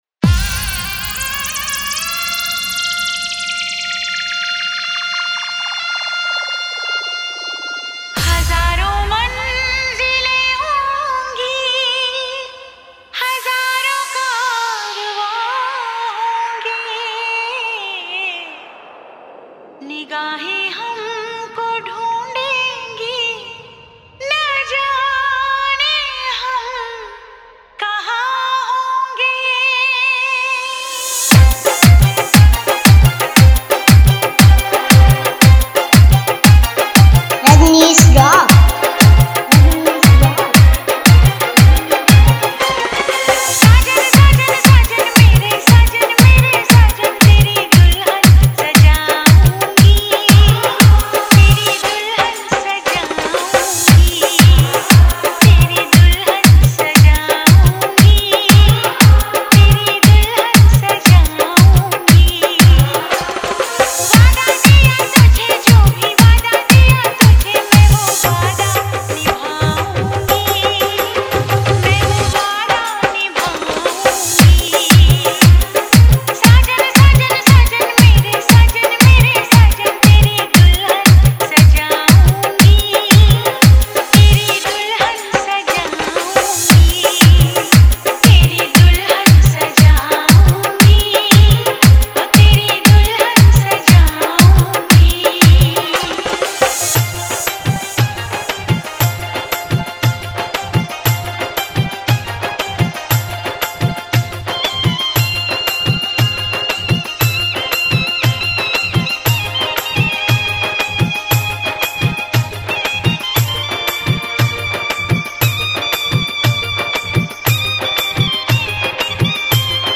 Wedding Dj Song